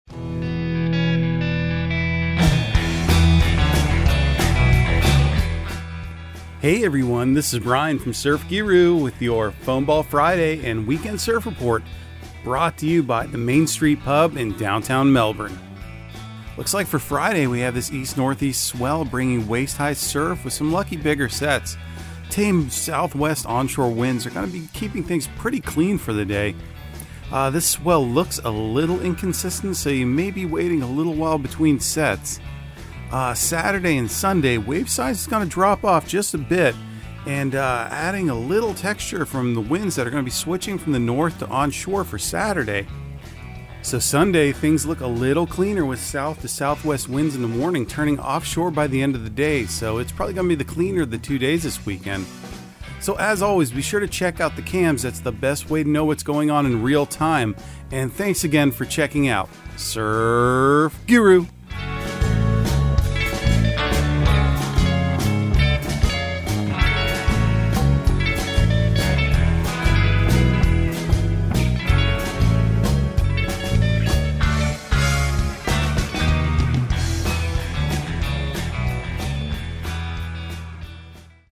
Surf Guru Surf Report and Forecast 11/25/2022 Audio surf report and surf forecast on November 25 for Central Florida and the Southeast.